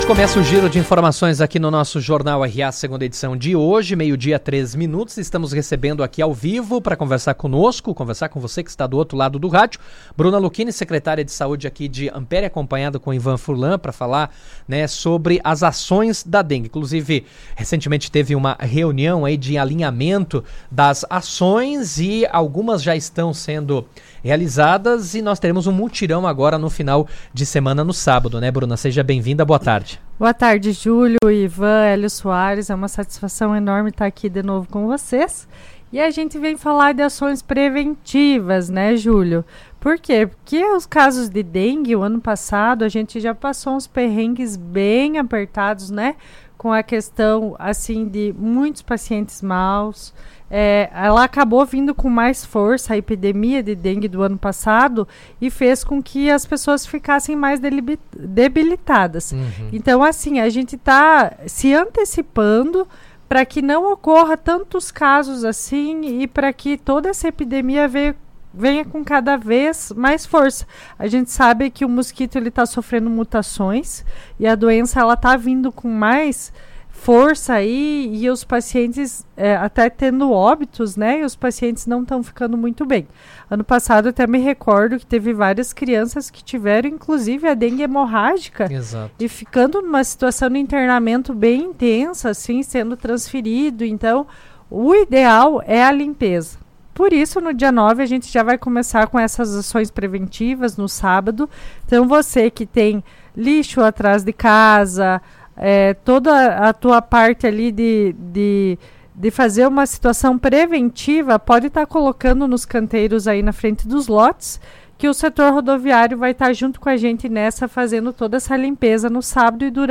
estiveram presentes no Jornal RA 2ª Edição nesta segunda-feira, 04, onde explicaram o trabalho que será realizado e destacaram a importância da participação da comunidade.